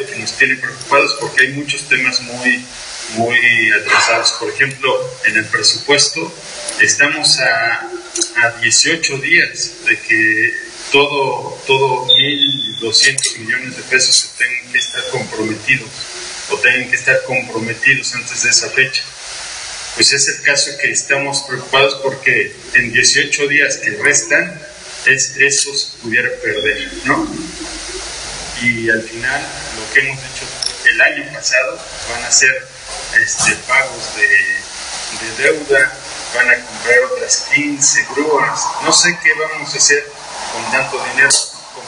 En conferencia de prensa, la regidora Marta Ornelas y Luis Acosta, a nombre de los integrantes del G5, coincidieron en que el asunto de la despenalización del aborto se convirtió en un “tema político” donde consideran que la primera regidora debe mantenerse al margen de las protestas que se han estado efectuando desde hace varios días por parte de activistas.